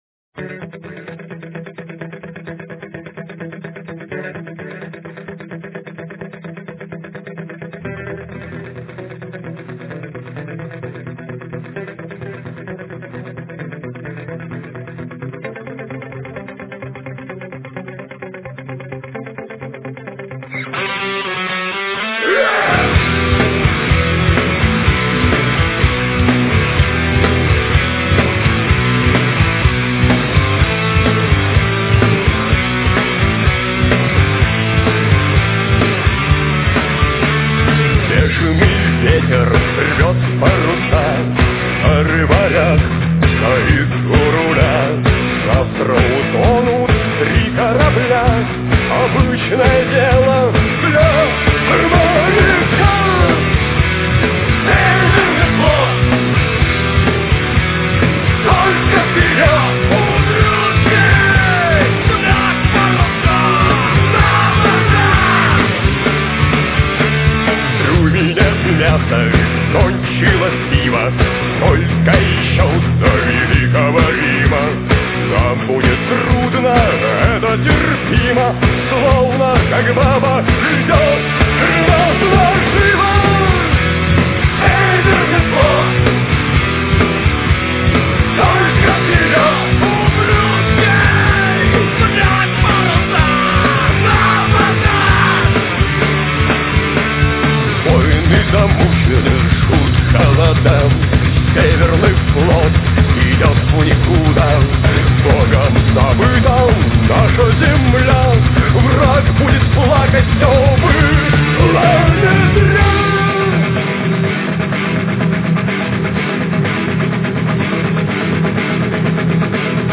Убойный буклет, 14 драйвовых треков в традиционной